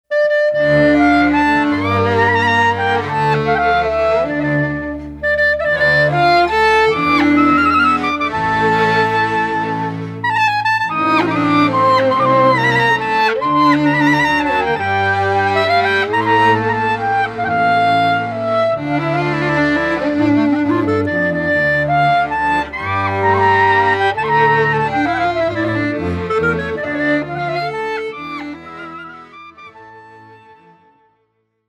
Violin
C Clarinet
Accordions, Tsimbl
Bass Cello
Genres: Klezmer, Polish Folk, Folk.